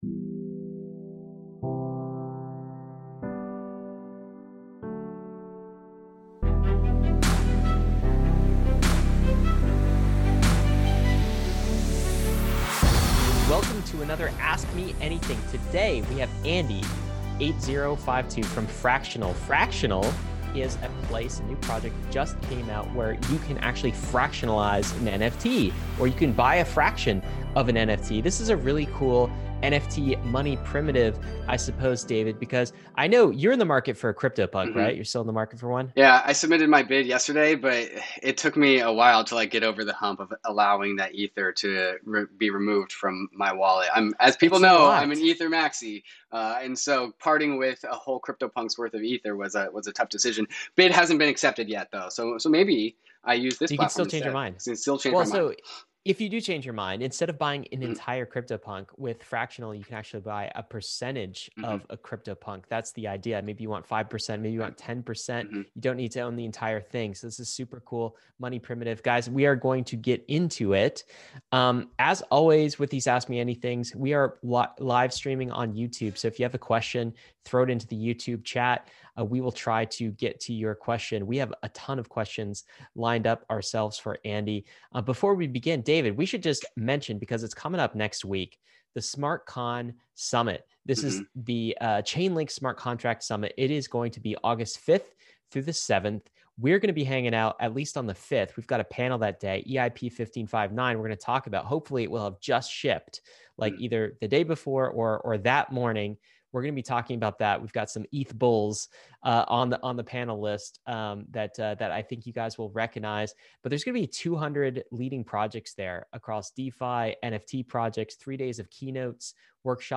Fractional | AMA